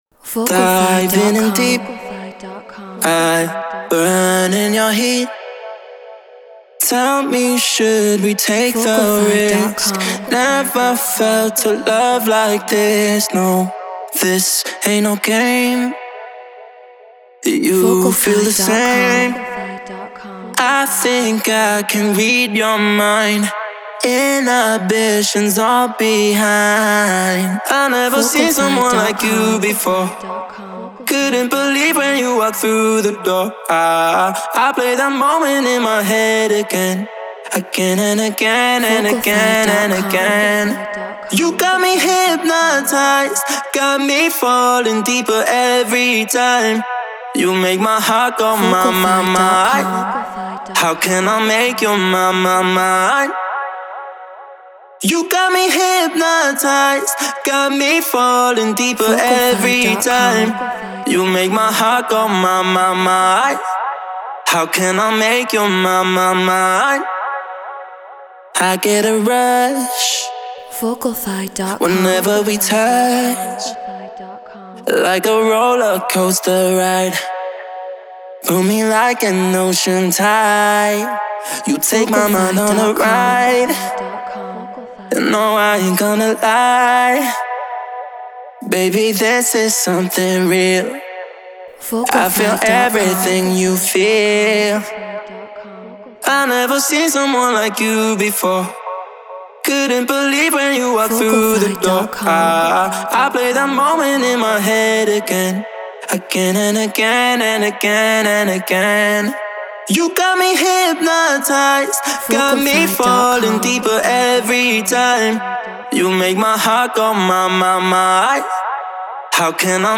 Eurodance 140 BPM F#min
Treated Room